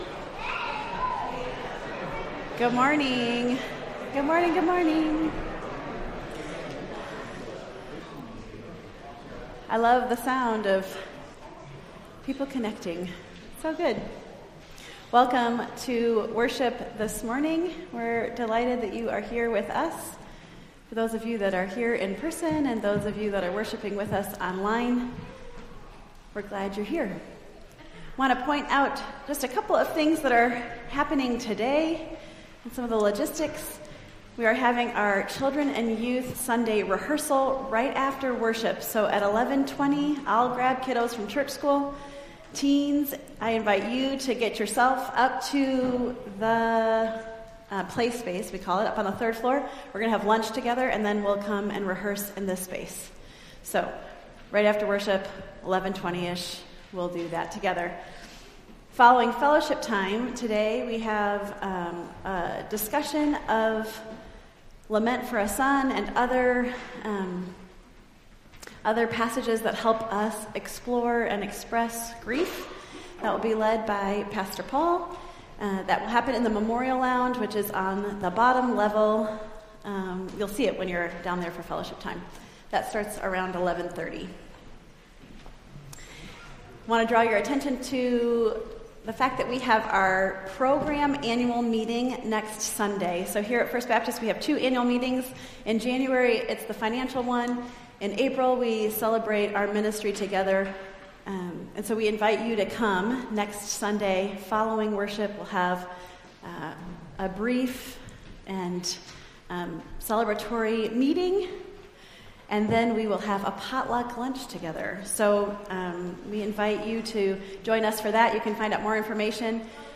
Entire April 23rd Service